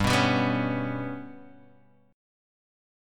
GMb5 chord